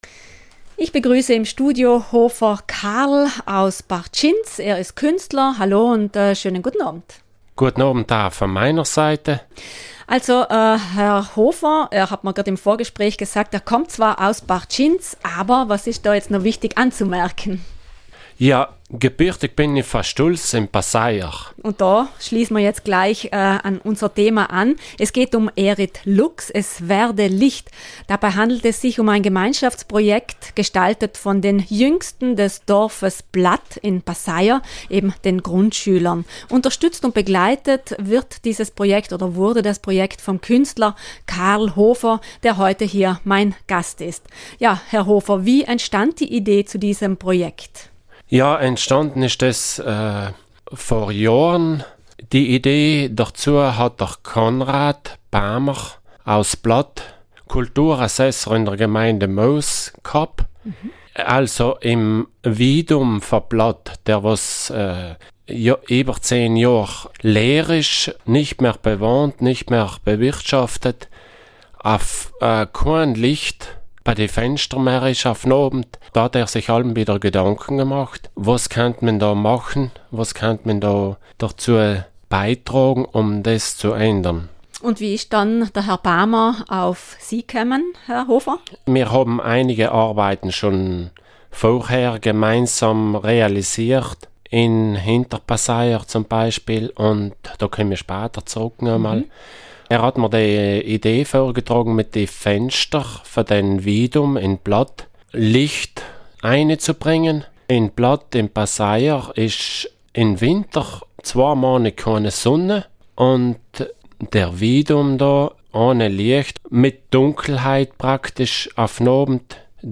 Radiointerview mit Künstler